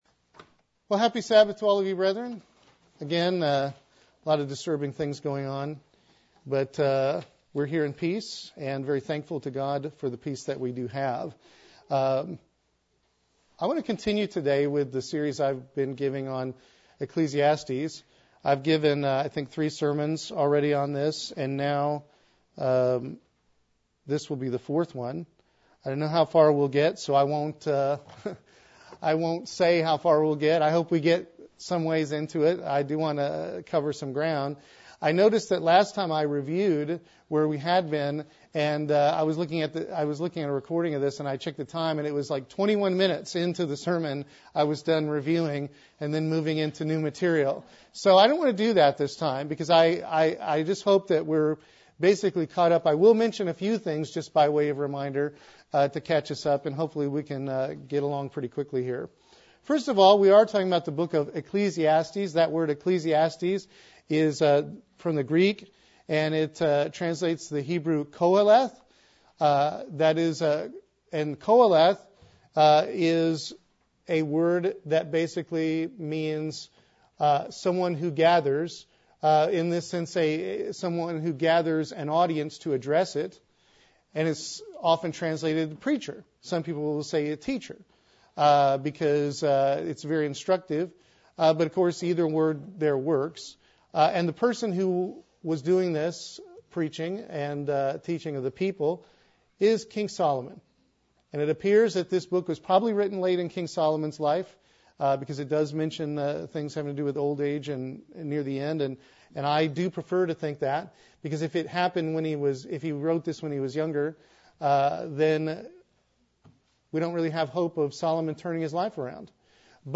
Sermons
Given in Columbia - Fulton, MO